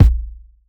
20 kick hit.wav